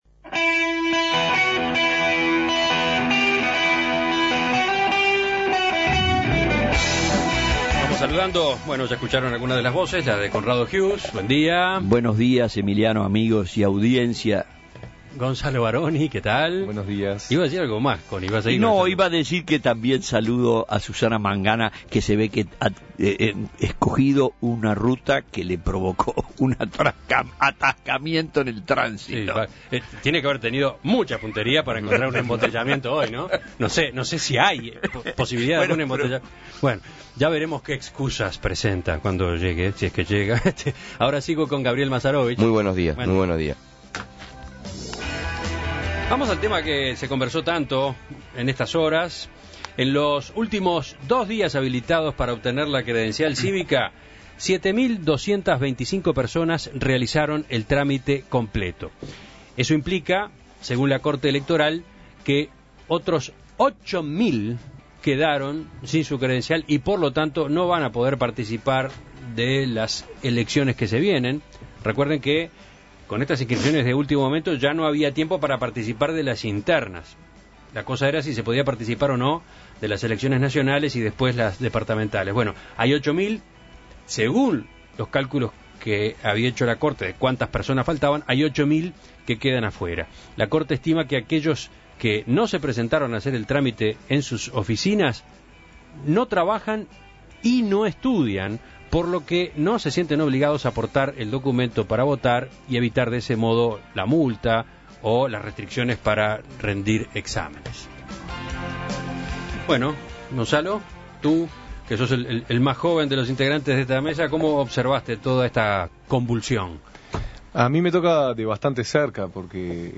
La ministra de la Corte Electoral, Margarita Reyes, dijo a En Perspectiva que ayer, último día del plazo, en Montevideo se completaron 2.000 trámites y se dejaron en curso 1.300 más que están pendientes de la verificación de ciertos datos pero que podrán terminarse y permitirán a sus titulares participar en las elecciones presidenciales y departamentales.